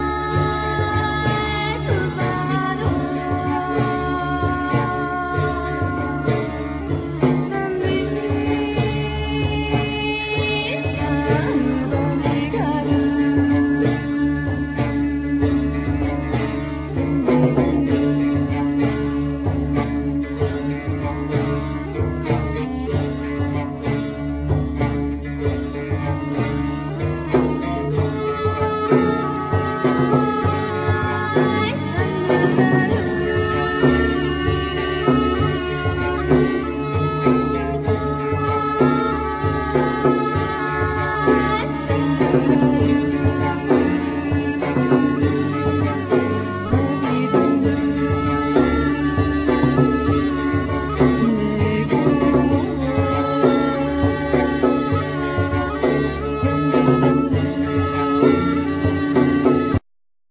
Guitar,Clarinett,Voice
Japanese orientalism